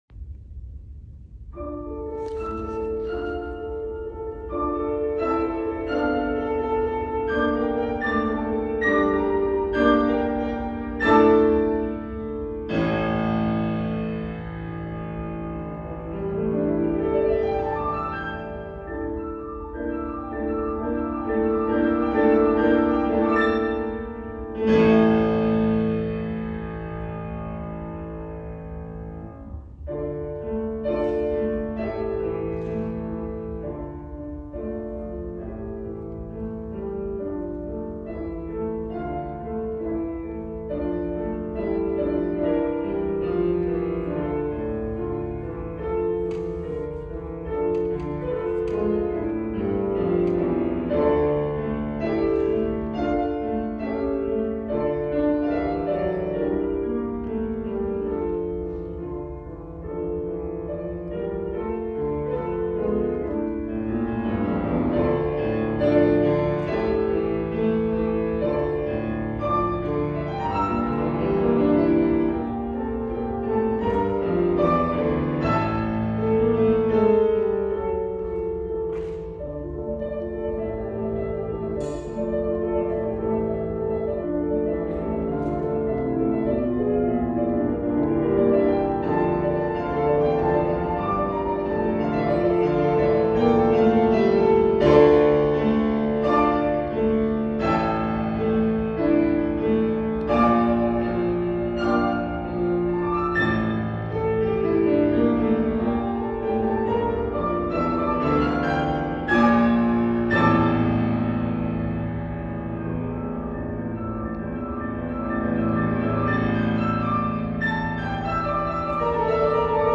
アイホンで録音した為音量が小さいと思いますが素晴らしい演奏お聞き下さい。